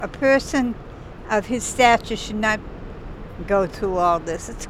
WOMAN WAITING FOR BUS AT SULLIVAN STATION T SAYS BRADY SHOULDN’T HAVE HAD TO GO THROUGH ALL THIS BECAUSE OF HIS STATURE